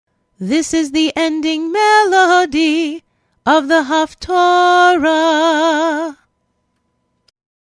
Haftarah Ending Melody
hp-endingmelody-1.mp3